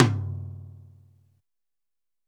MID-TOM 900.WAV